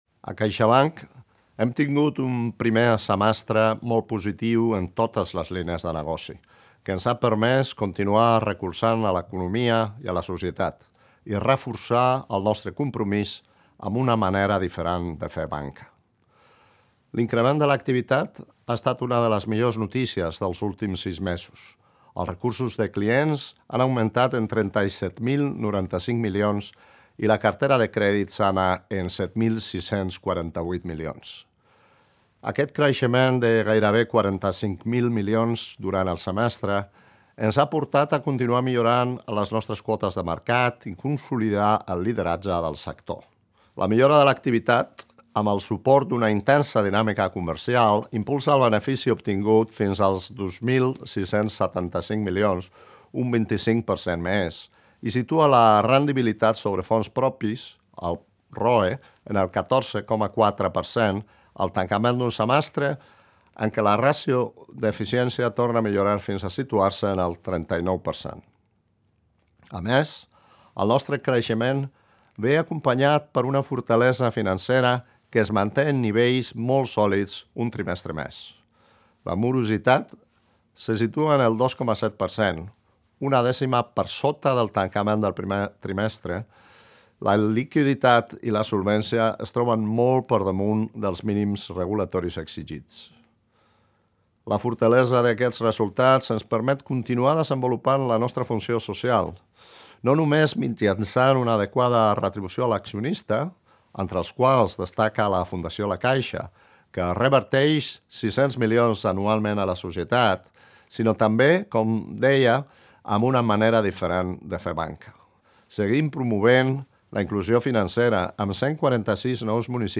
Àudio del CEO de CaixaBank, Gonzalo Gortázar